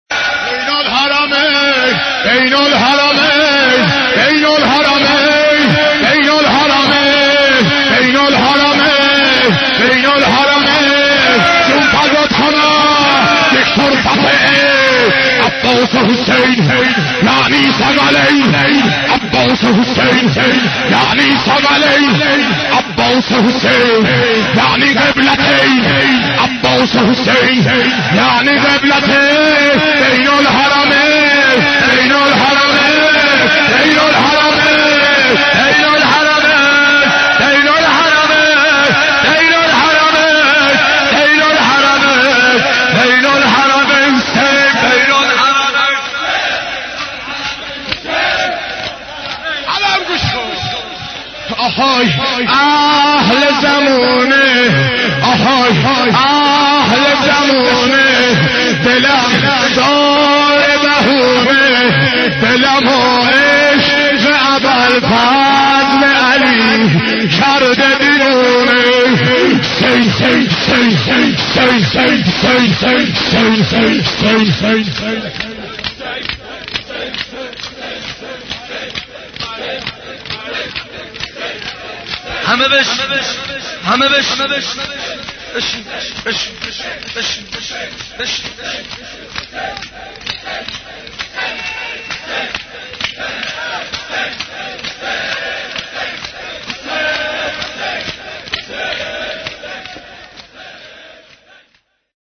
حضرت عباس ع ـ شور 27